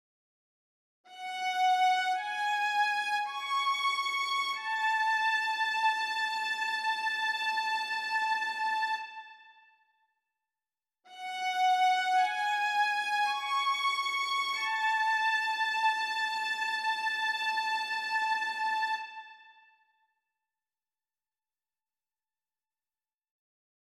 １．ゲートタイム（GT）を長めにとって，音同士を重ねる
mp3の前半（画面写真では上段）と後半（同下段）を聴き比べてみて下さい．
違いがわかりやすいよう，あえてフォルテシモで弾かせています．